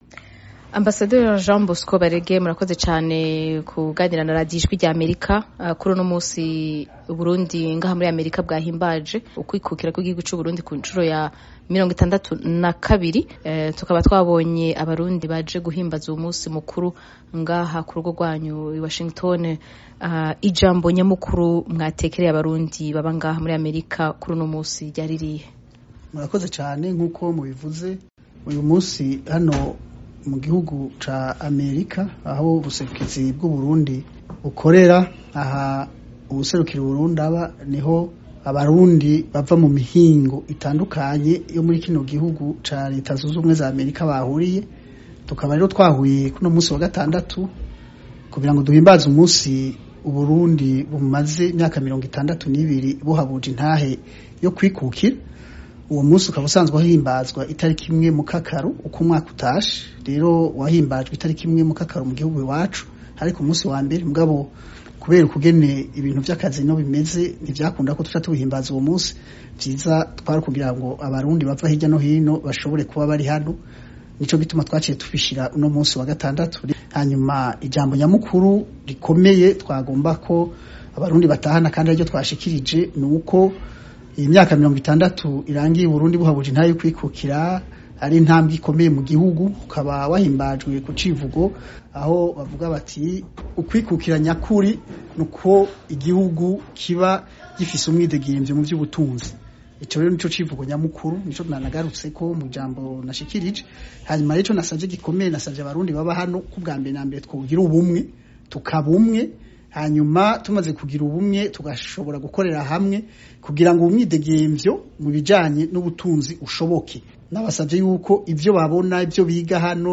Ikiganiro Cihariye na Ambasaderi Jean Bosco Barege